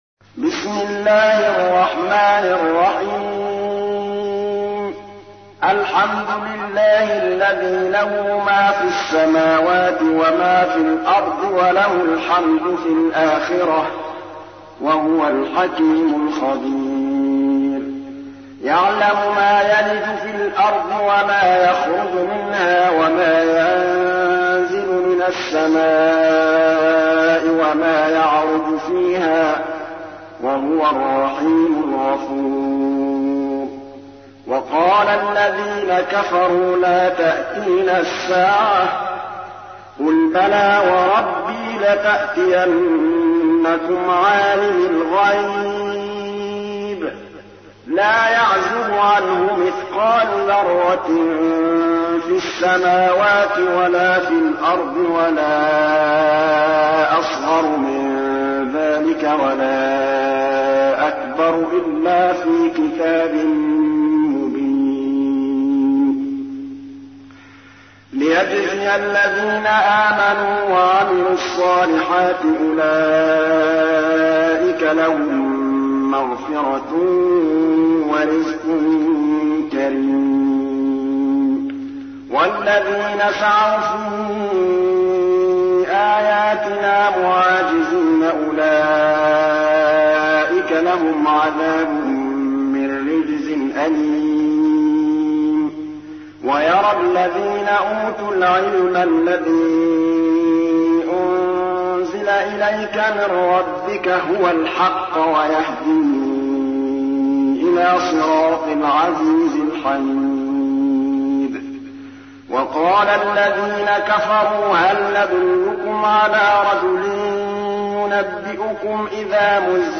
تحميل : 34. سورة سبأ / القارئ محمود الطبلاوي / القرآن الكريم / موقع يا حسين